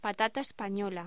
Locución: Patata española
voz